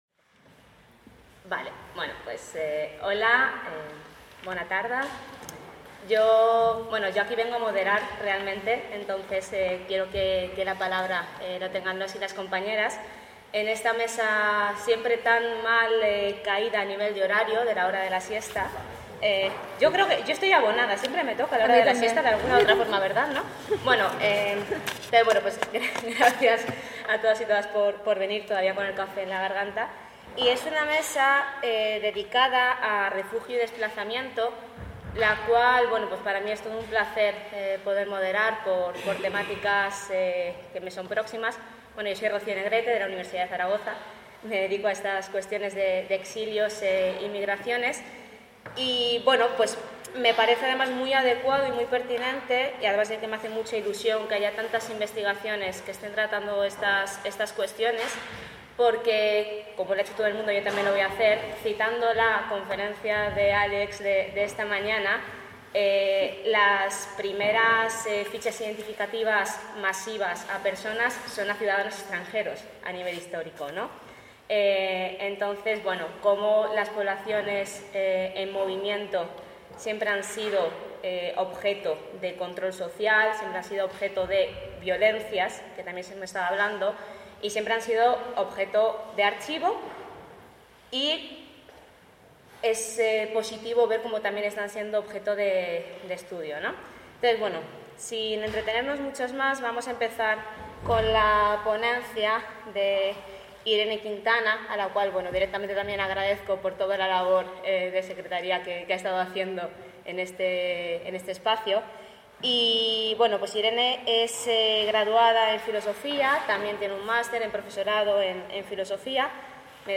taula rodona